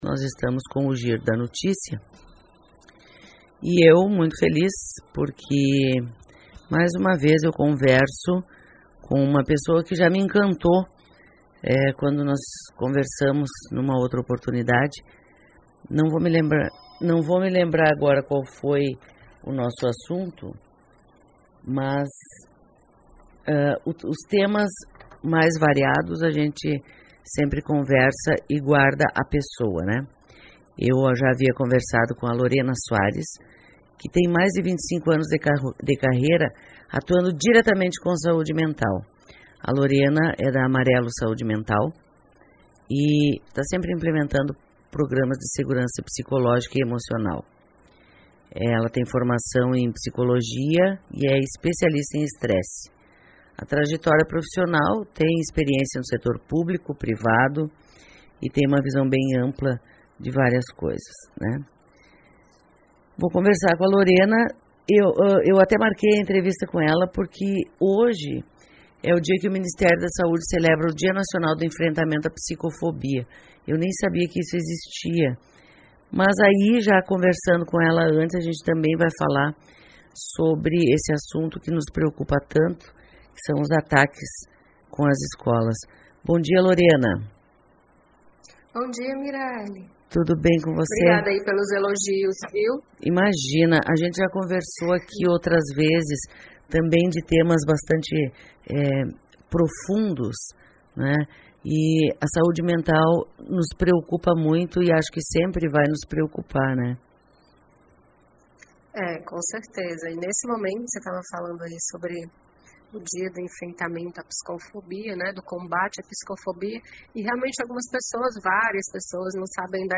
Entrevista: Dia da Conscientização contra a Psicofobia - saiba o que é